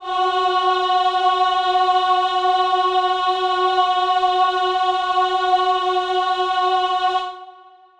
Choir Piano (Wav)
F#4.wav